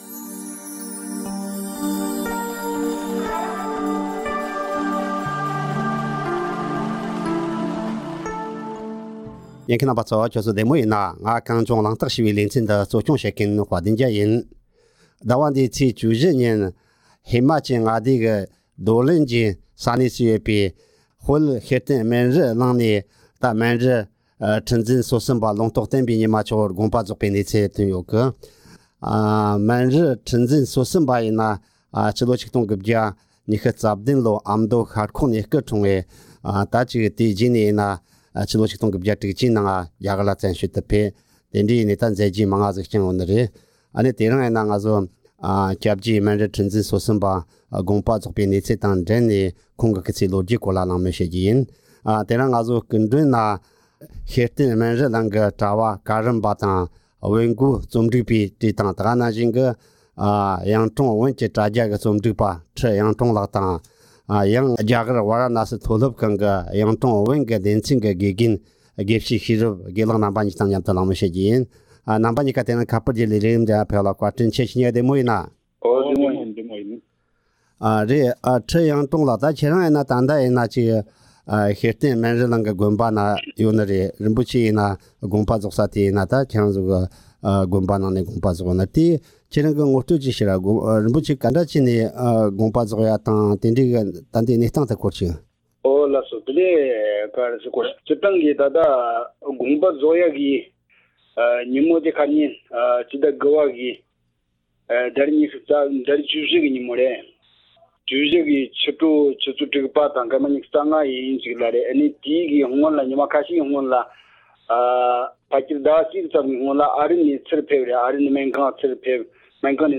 སྐུ་ཚེ་ལོ་རྒྱུས་དང་མཛད་རྗེས་ཐད་གླེང་མོལ།